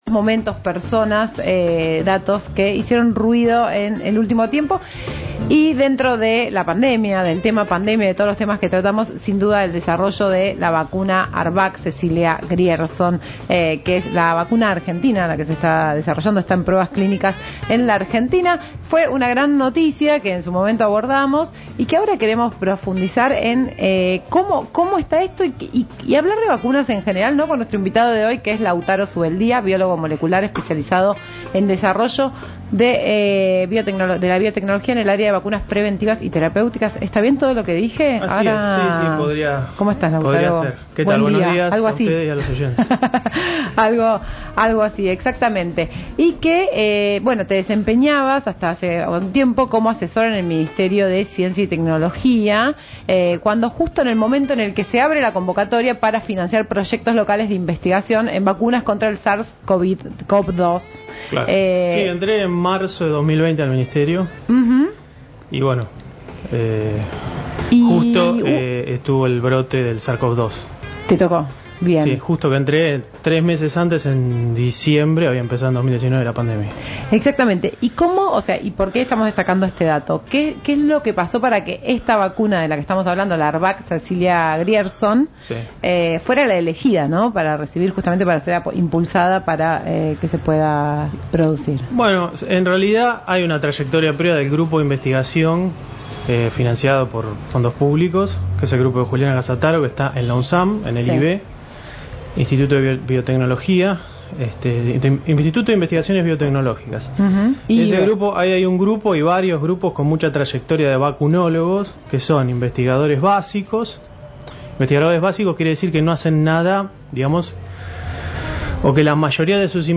Entrevista en ¨Todo Ruido¨ -